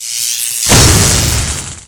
SFX雷电闪电魔法音效下载
SFX音效